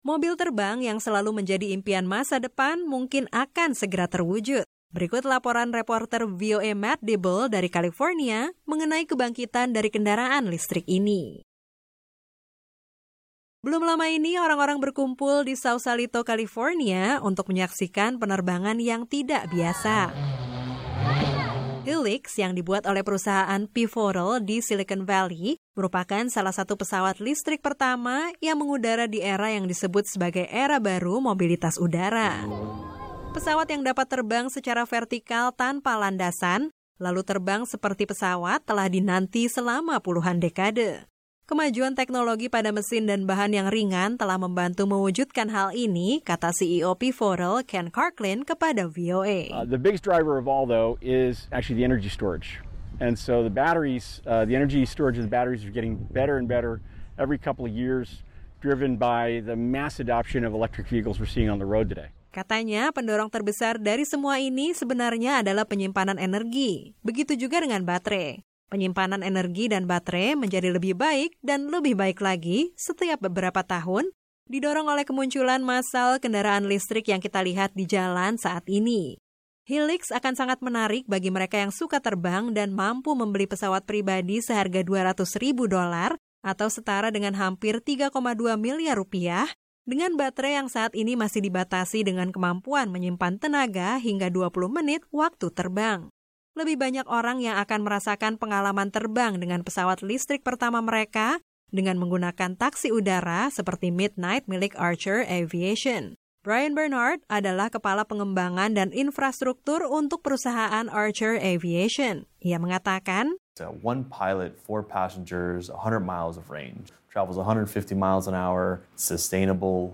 Mobil terbang yang selalu menjadi impian masa depan mungkin akan segera terwujud. Berikut laporan reporter VOA